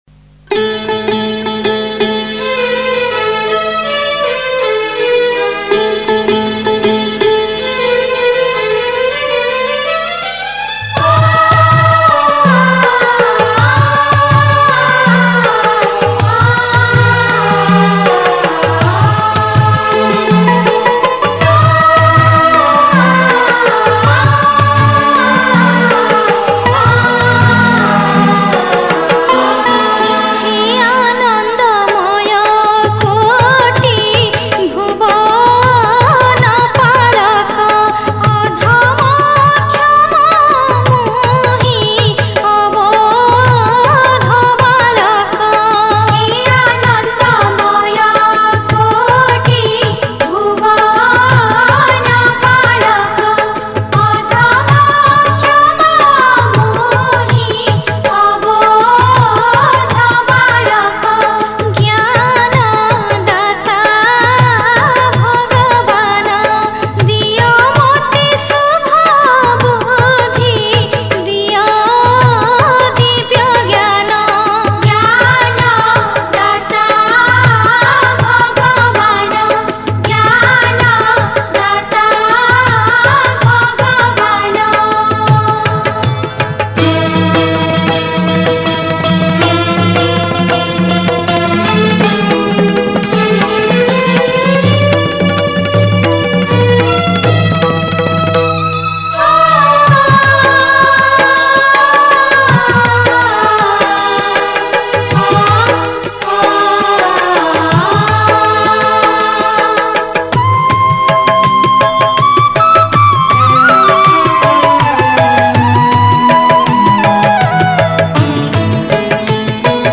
Category: Prathana